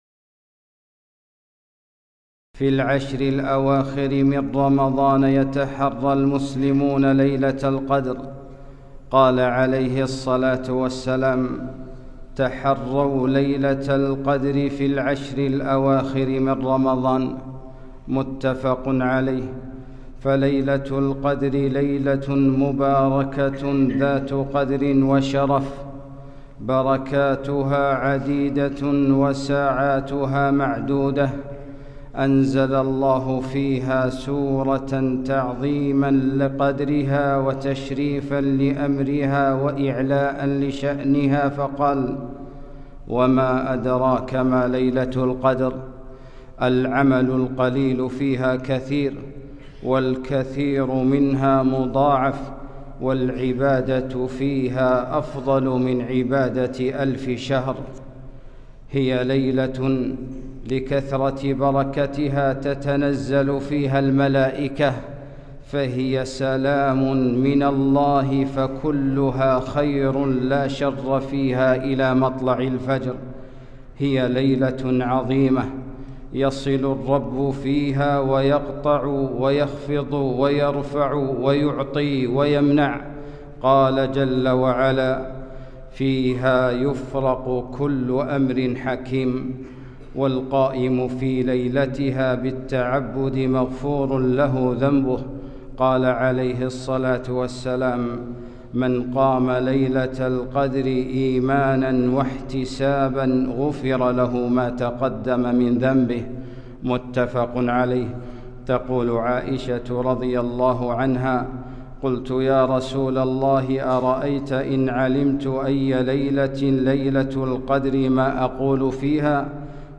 خطبة - العشر الأواخر